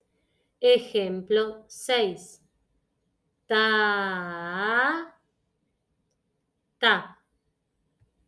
DICTADO DE FIGURAS EJEMPLO 6